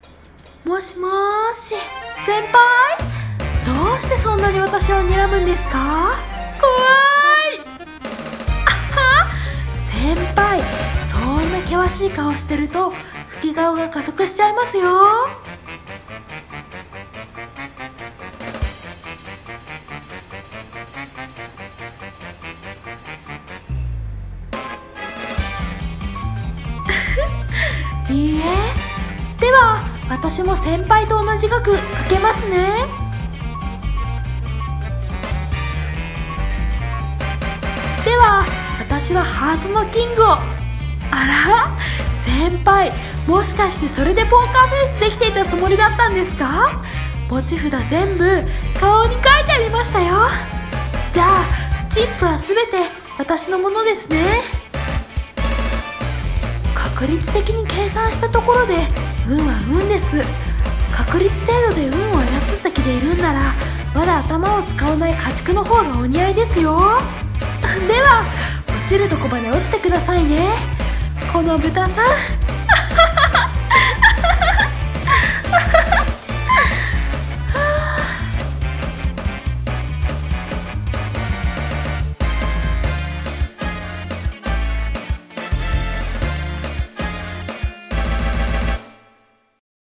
二人声劇「ポーカーフェイス」ギャンブル台本